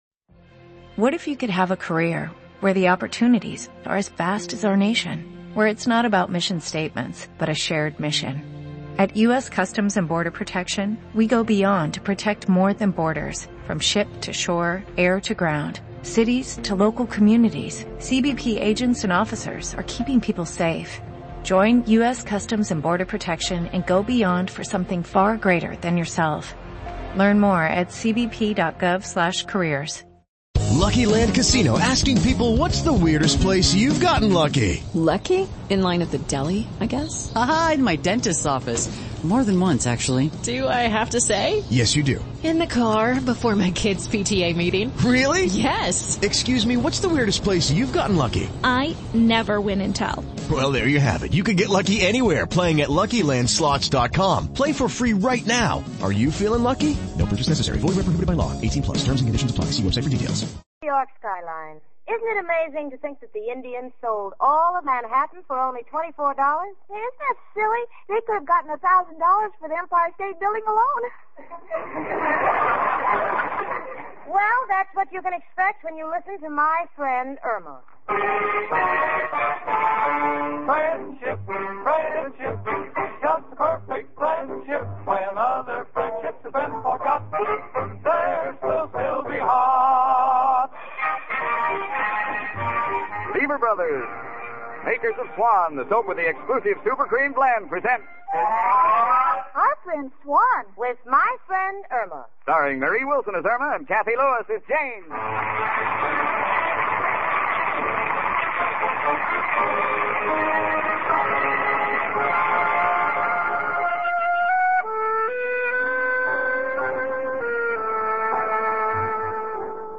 "My Friend Irma," the classic radio sitcom that had audiences cackling from 1946 to 1952! It was a delightful gem of a show, chronicling the misadventures of Irma Peterson, a ditzy yet endearing blonde, and her level-headed roommate Jane Stacy. Irma, played to perfection by the inimitable Marie Wilson, was the quintessential "dumb blonde."